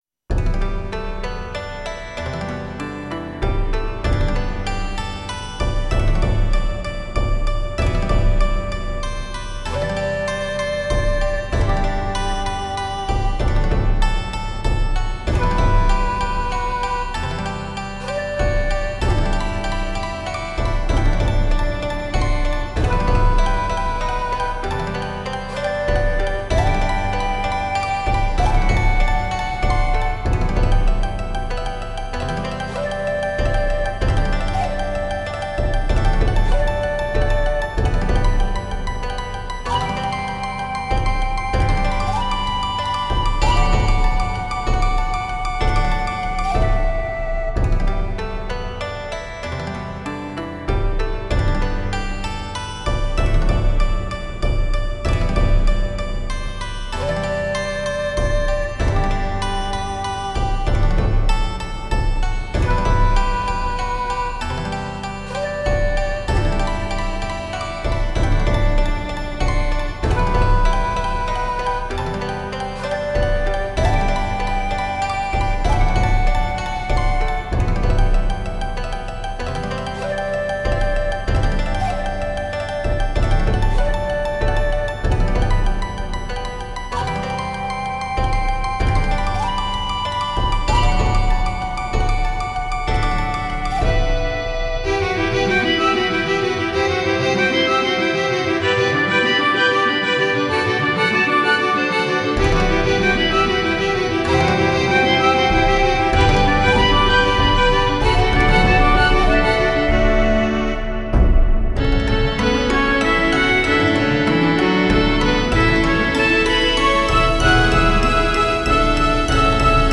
Instrumental Of The Year: Media